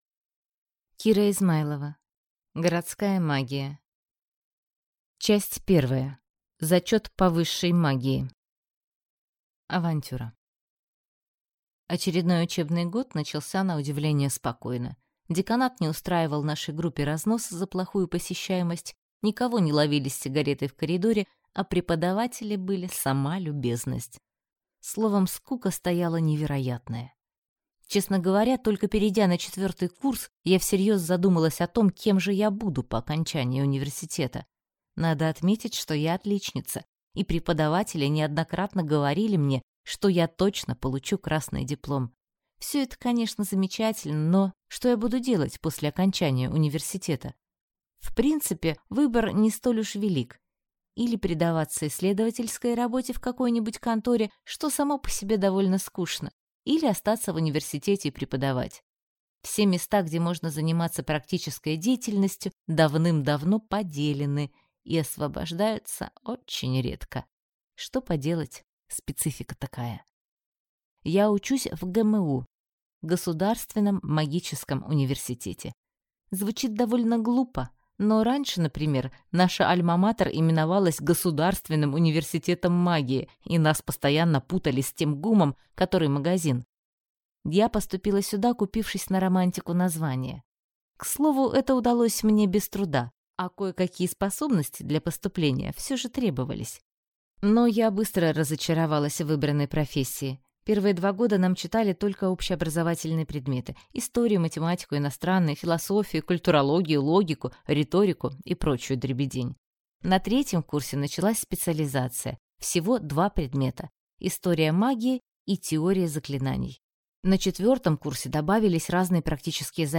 Аудиокнига Городская магия | Библиотека аудиокниг
Прослушать и бесплатно скачать фрагмент аудиокниги